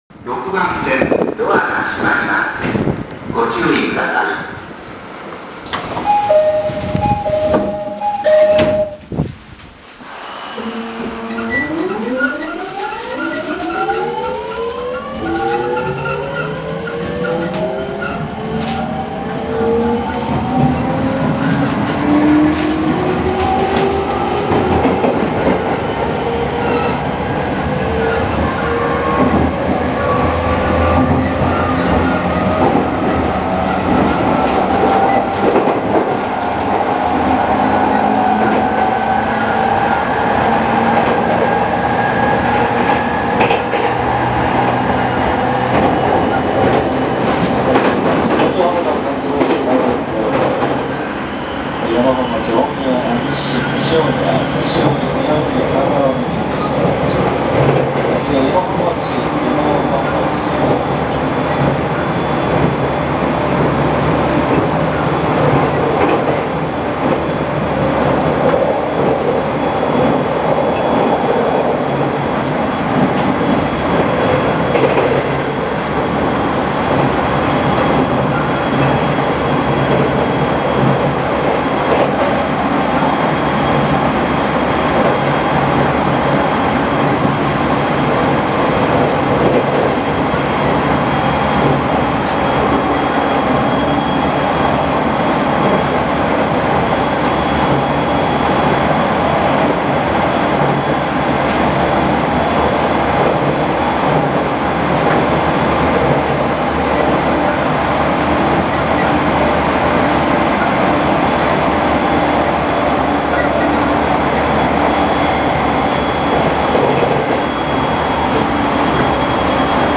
・70-000形三菱GTO走行音
【JR埼京線】武蔵浦和〜与野本町（3分53秒：1.23MB）
基本的にJR東日本の209系と変わりないので、209系と同じ三菱GTOを採用していました。